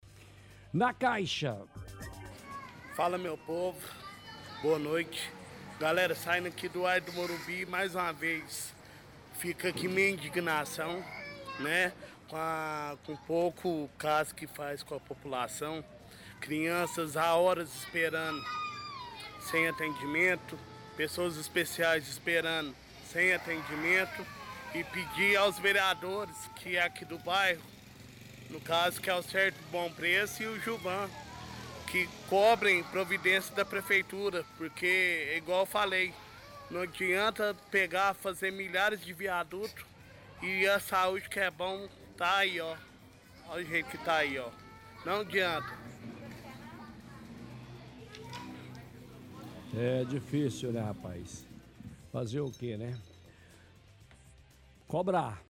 – Ouvinte reclama da UAI Morumbi sobre demora pra atender UAI lotada cita que vereador Gilvan e Sérgio Bom Preço cobrar da prefeitura melhoras.